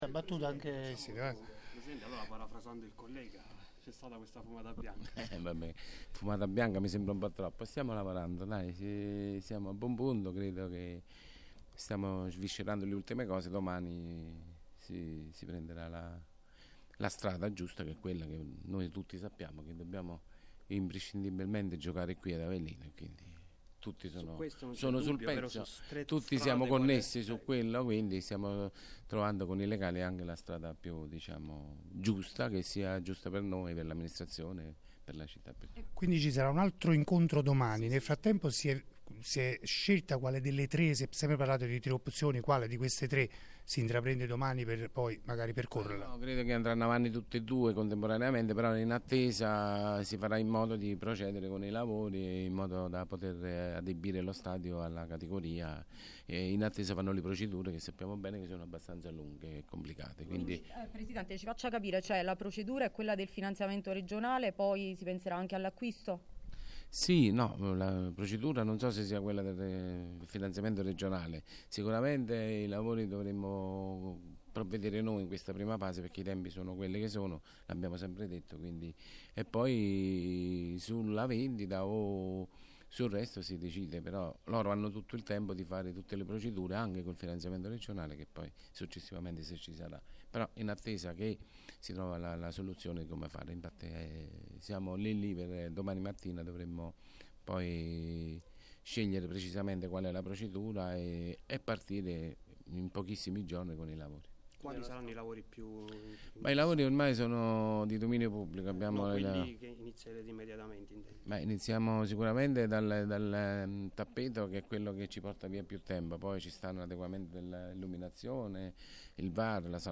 A MARGINE DELL’INCONTRO CON LA SINDACA NARGI A PALAZZO DI CITTÀ